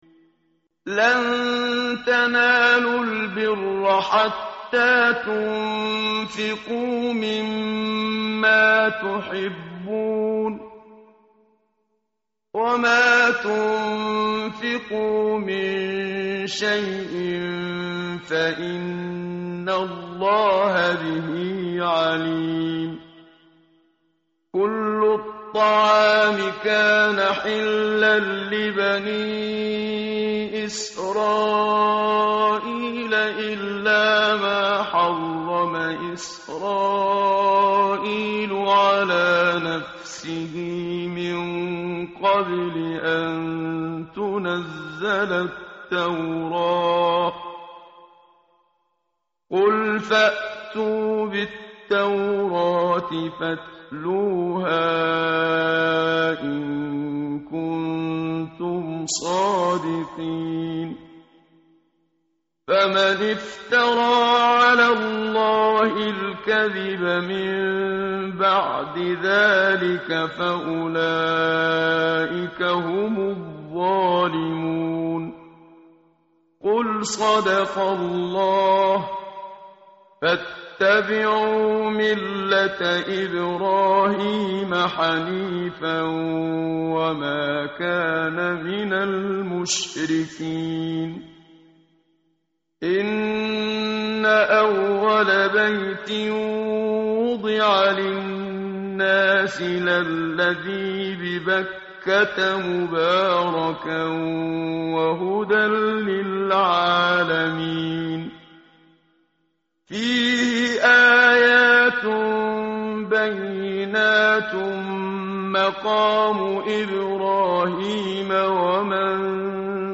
متن قرآن همراه باتلاوت قرآن و ترجمه
tartil_menshavi_page_062.mp3